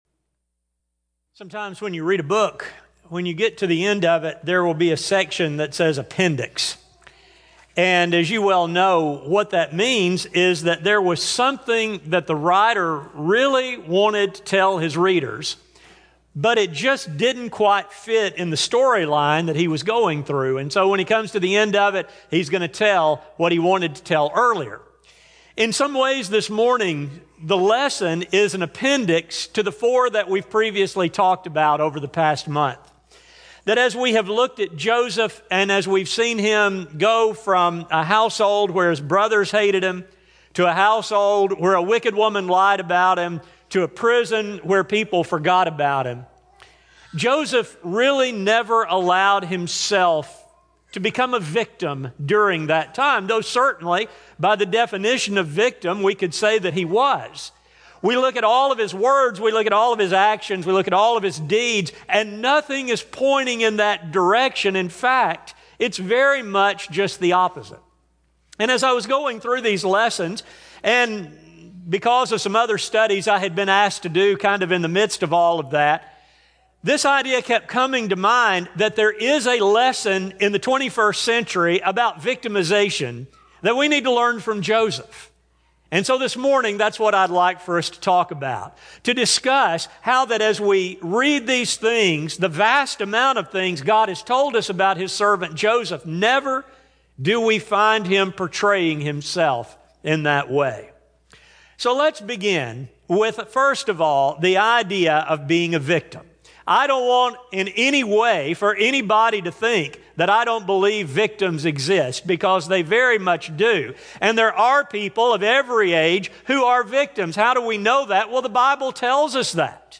Lessons From The Life Of Joseph Service: Sun AM Type: Sermon Speaker